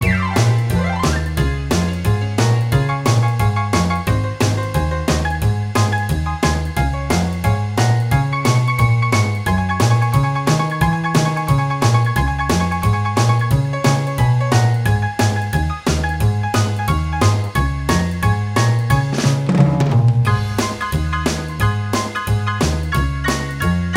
Minus Guitars Rock 'n' Roll 3:01 Buy £1.50